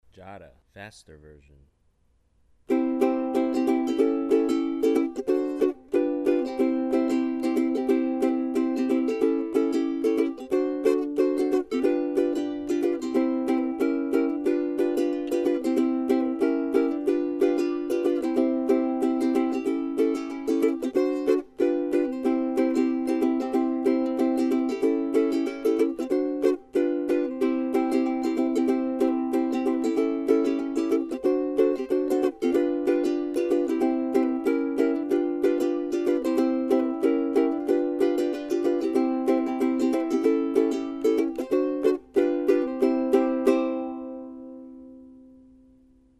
Fast version audio